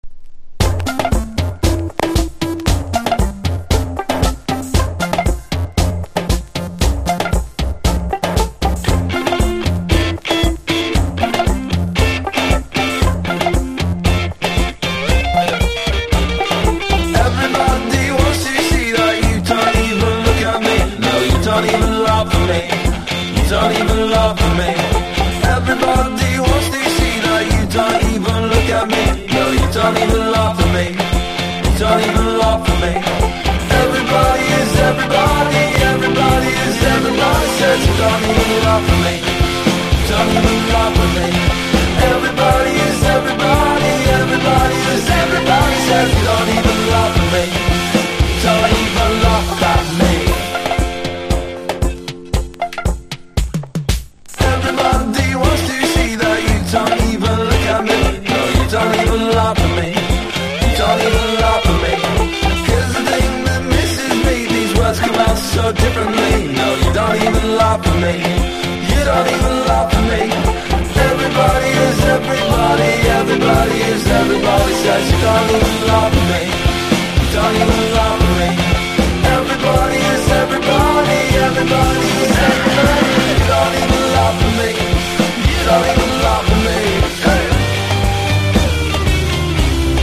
INDIE DANCE